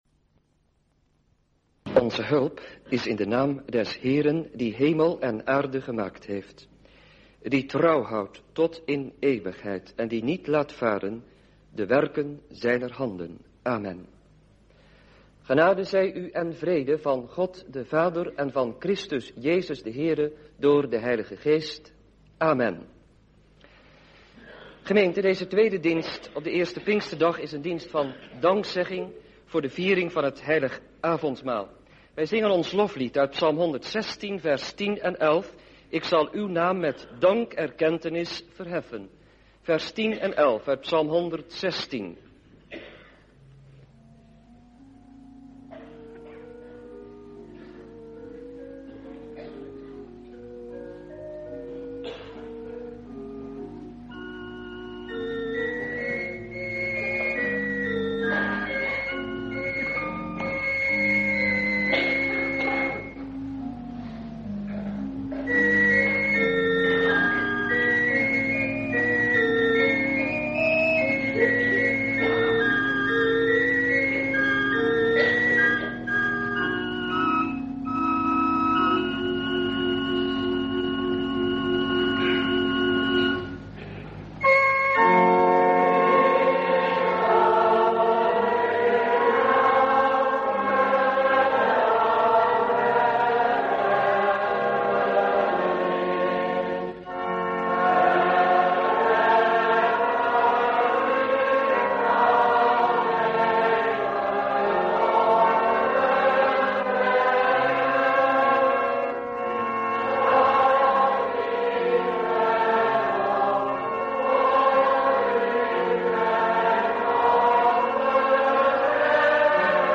Schriftlezing: Handelingen der Apostelen 2: 22-36